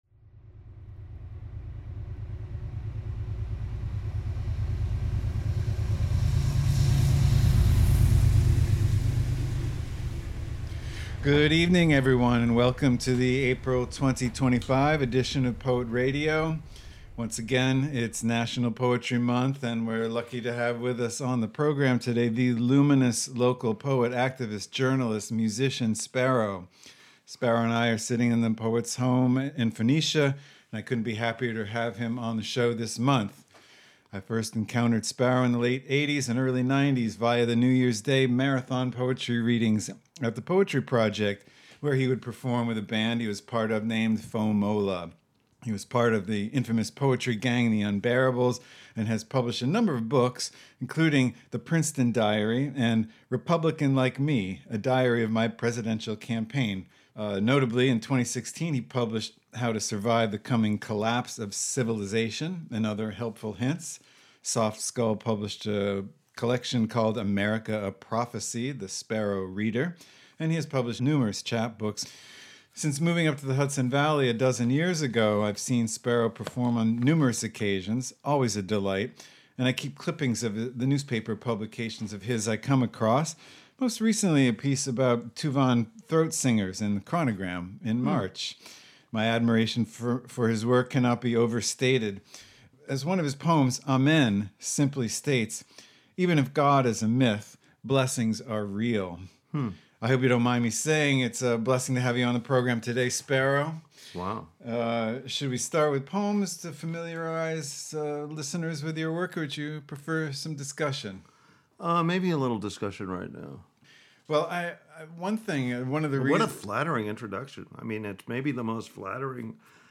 Blending words and sounds, bringing to the airwaves live performances as well as field and studio recordings by writers the host has crossed paths with over the course of a quarter century.